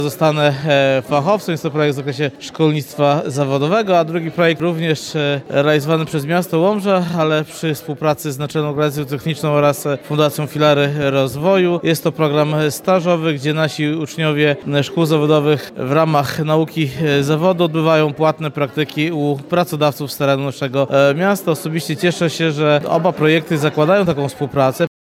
W Hali Kultury w Łomży podczas Gali Biznesu i Edukacji podsumowano dwa ważne projekty realizowane we współpracy ze szkołami zawodowymi oraz pracodawcami.
O tym, na czym polegały projekty, mówił wiceprezydent Łomży Andrzej Stypułkowski.